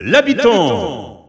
Announcer pronouncing male Villager in French on victory screen.
Villager_French_Alt_Announcer_SSBU.wav